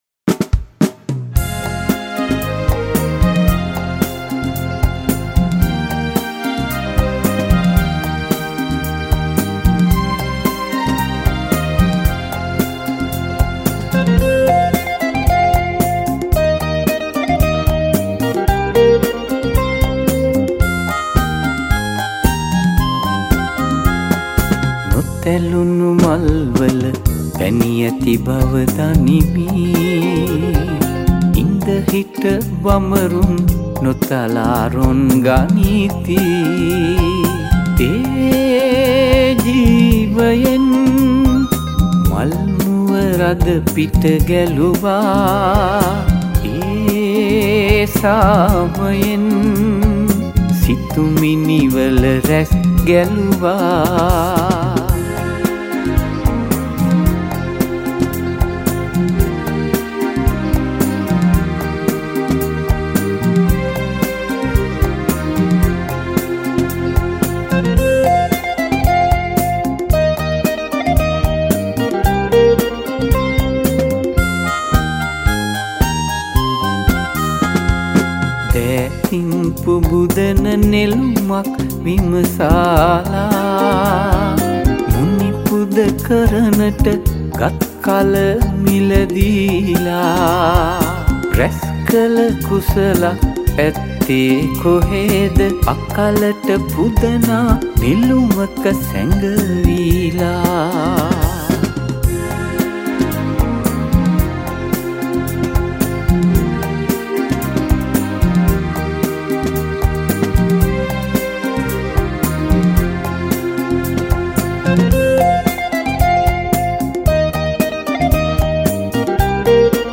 at the Sage Studio, Sri Lanka